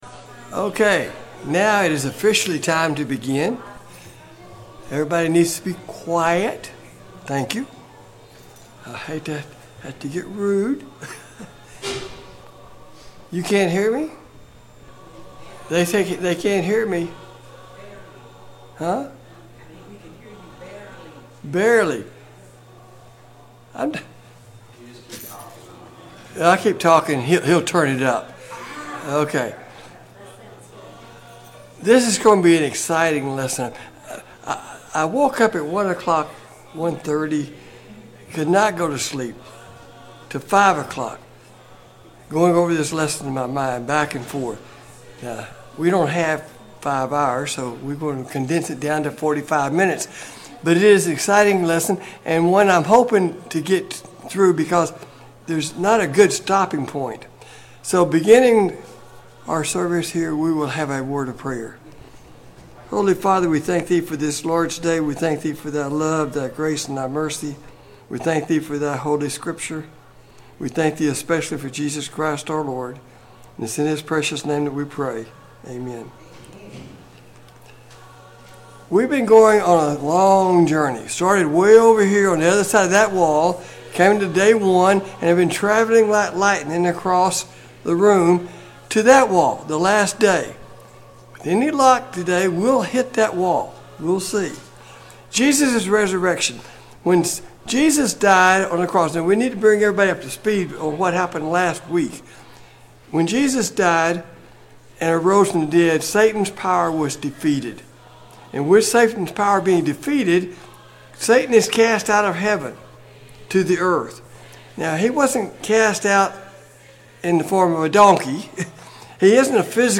Sunday Morning Bible Class « Study of Paul’s Minor Epistles